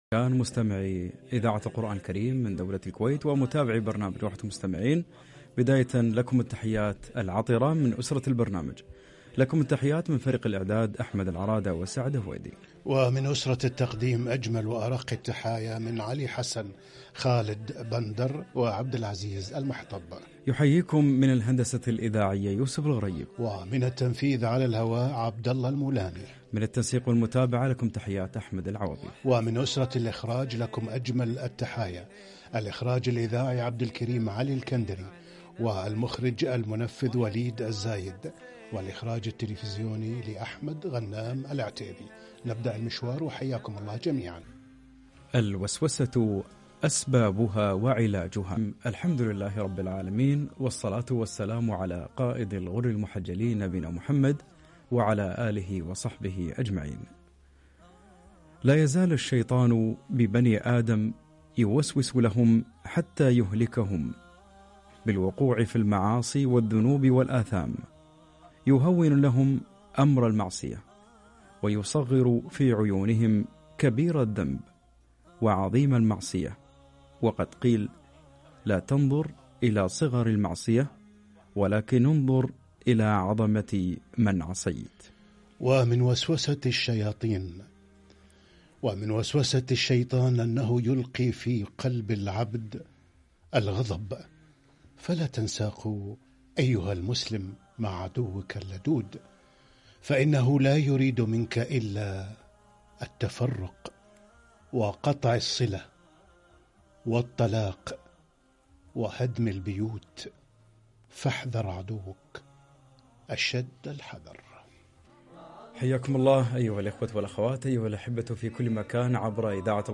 الوسواس أسبابه وعلاجه - لقاء إذاعي